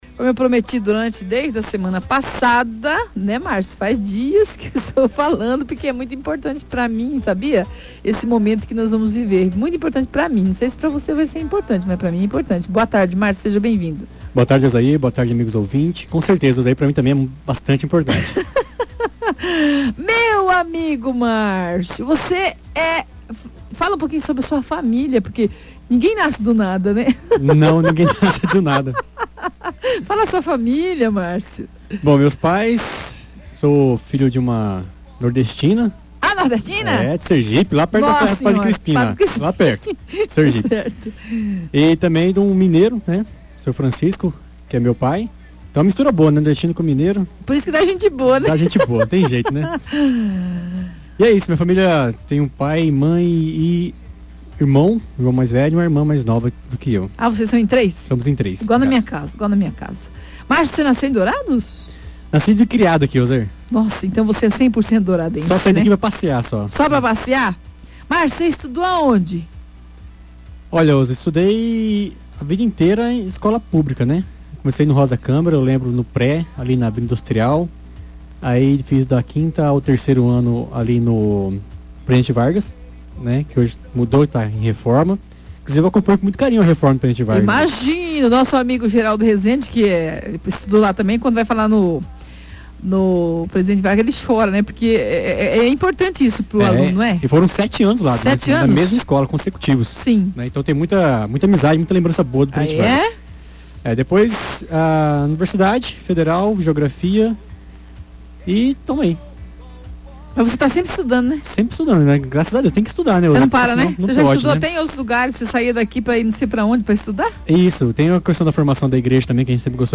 Ouça a emocionante entrevista